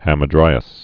(hămə-drīəs)